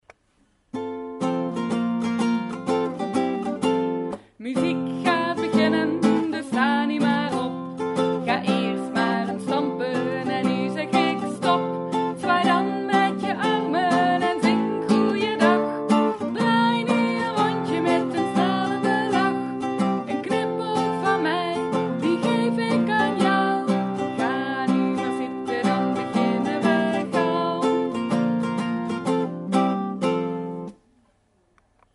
openings- en slotliedjes van de muziekles